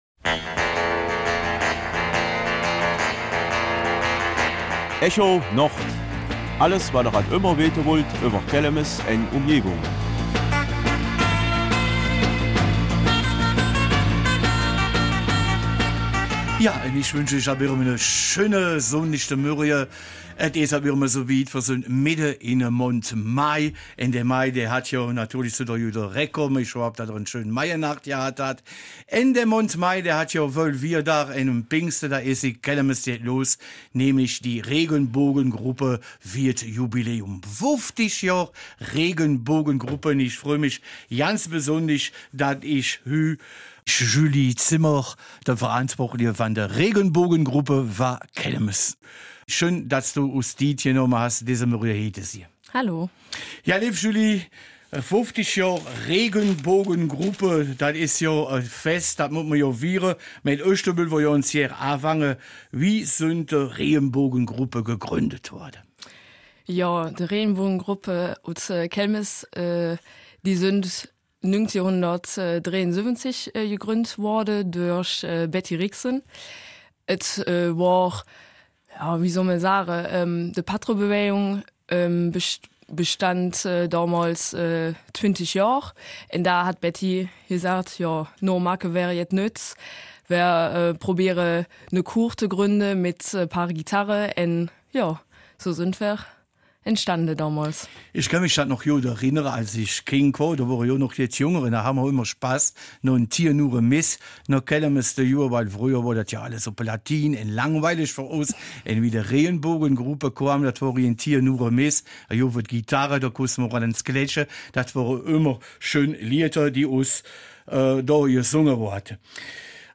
Kelmiser Mundart: 50 Jahre Regenbogengruppe Kelmis